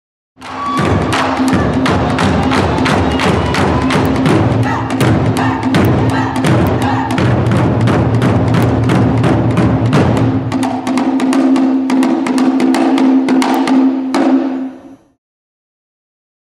Taiko ist ...japanisches Trommeln, Bewegung/Tanzen und Rufen/Singen zugleich.